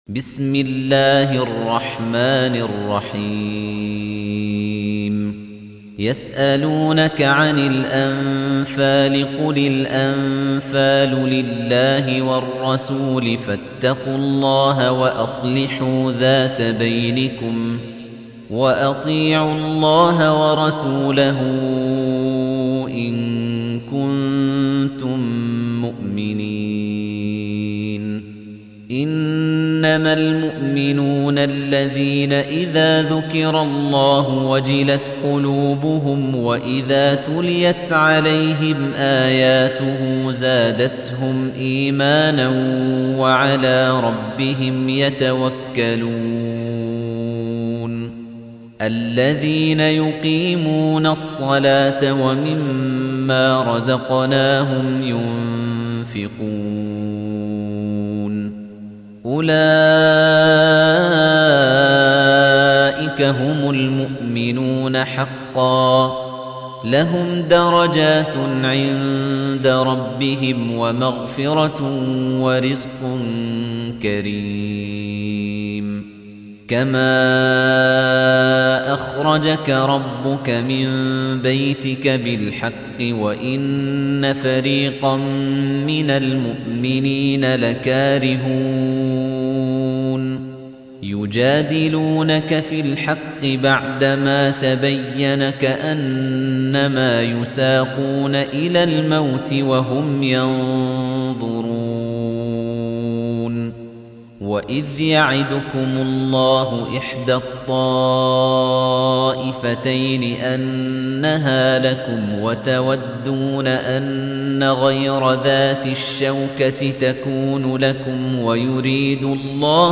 تحميل : 8. سورة الأنفال / القارئ خليفة الطنيجي / القرآن الكريم / موقع يا حسين